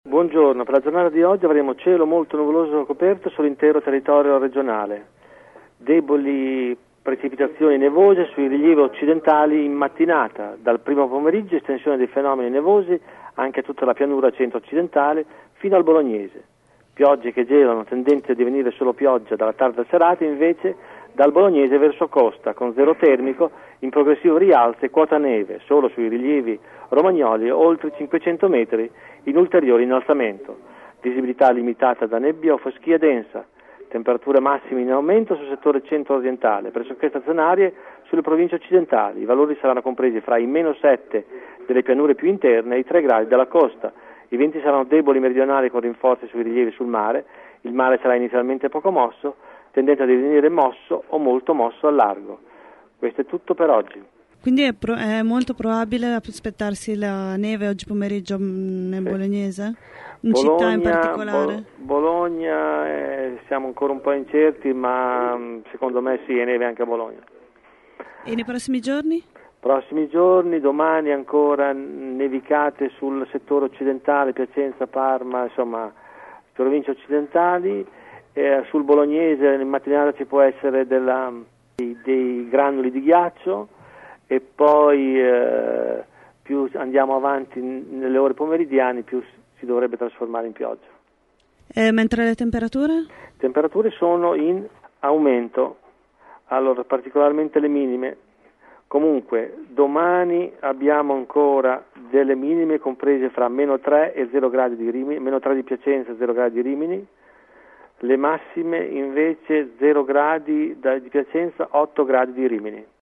Queste le previsioni meteo dell’Arpa di Bologna: